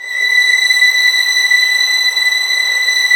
Index of /90_sSampleCDs/Roland - String Master Series/STR_Vlns 6 mf-f/STR_Vls6 mf%f St